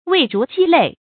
讀音讀法：
味如雞肋的讀法